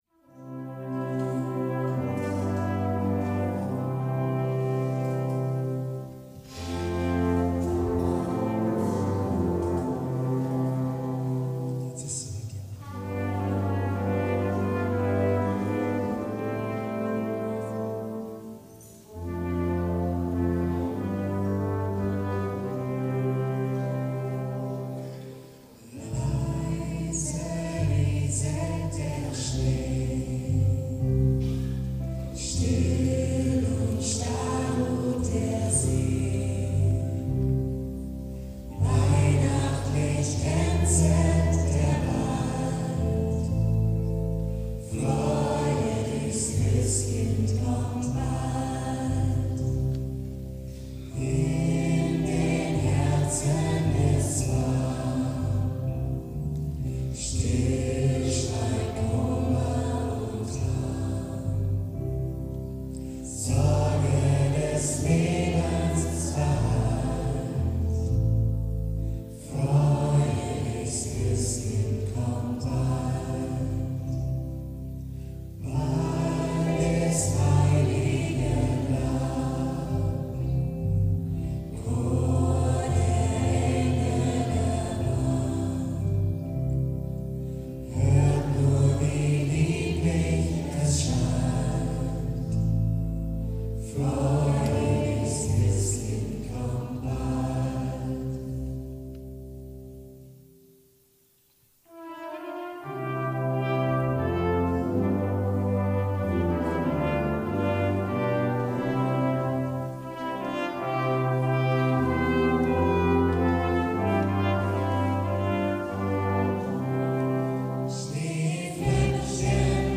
wenn die erste Strophe erklingt und unsere Stimmen eins werden, spüren wir: Weihnachten ist kein Solo, sondern ein gewaltiger Chor der Hoffnung.
live während des gemeinsamen Singens in unserem Weihnachtskonzert entstanden.
20-Weihnachtsliedermedley.mp3